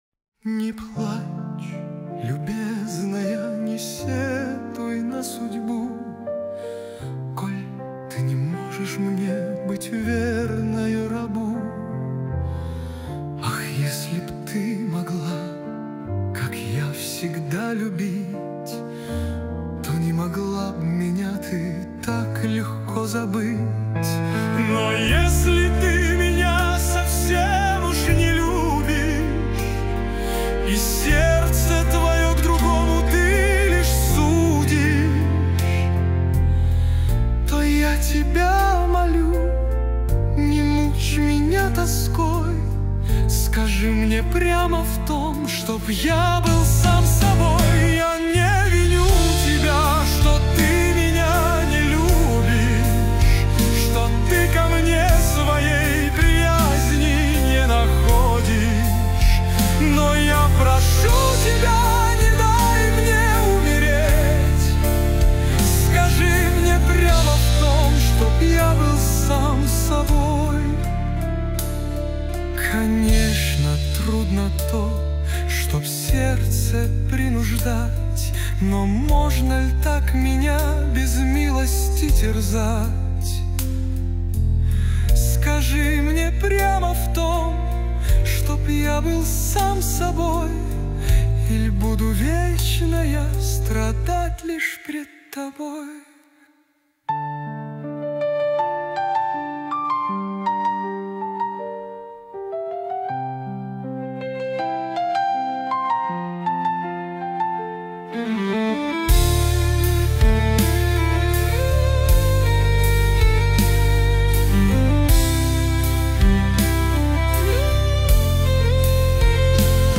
13 декабрь 2025 Русская AI музыка 91 прослушиваний